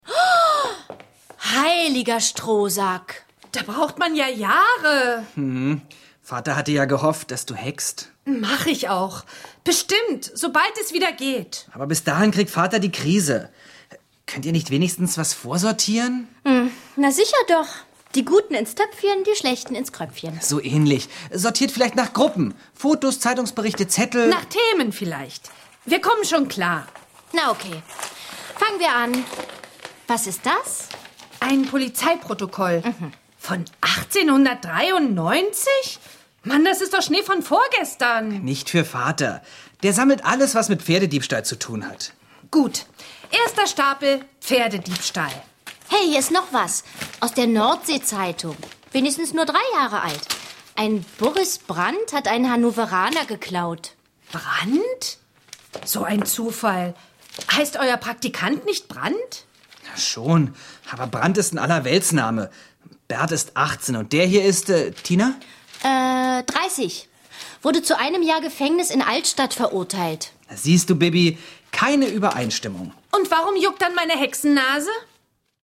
Ravensburger Bibi und Tina - Der Pferdedieb ✔ tiptoi® Hörbuch ab 6 Jahren ✔ Jetzt online herunterladen!